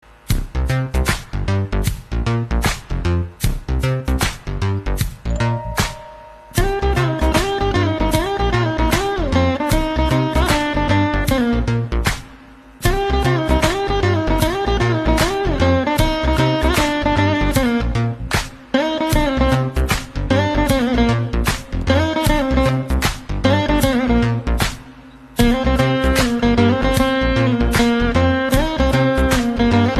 Category: Hindi Ringtones